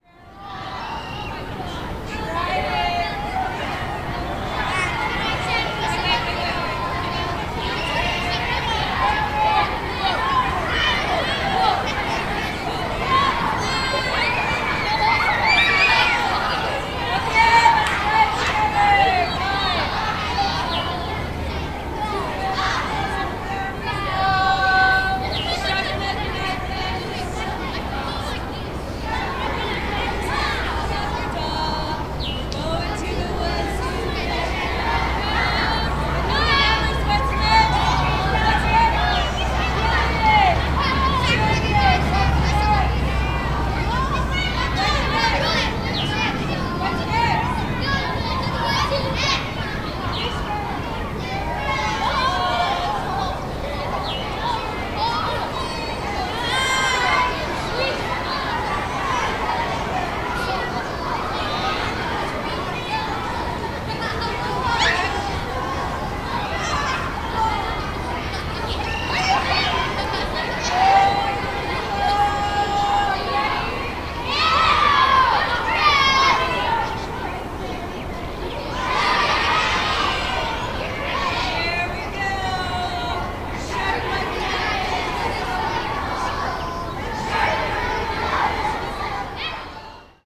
Spring trip to Yosemite Valley
Children at play
019_children_groupPlay.ogg